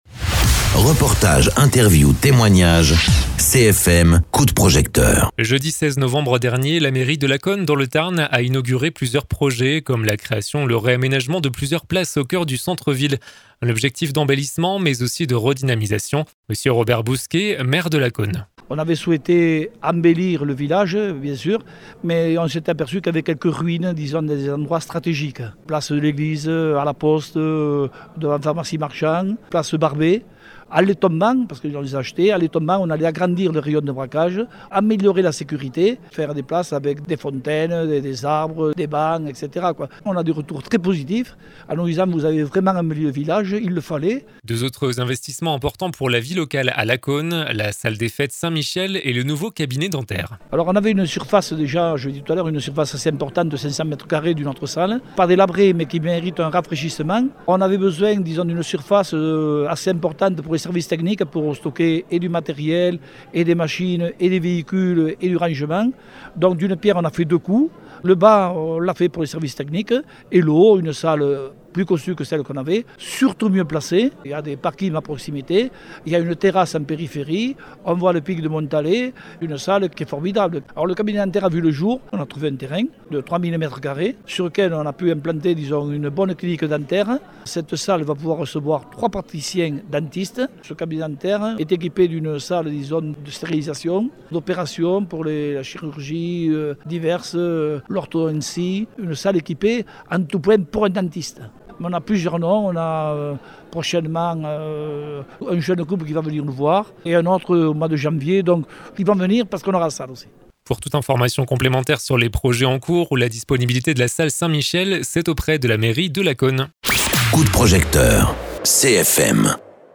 Interviews
Invité(s) : Mr Le Maire de Lacaune, Robert Bousquet.